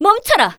cleric_f_voc_skill_holybolt.wav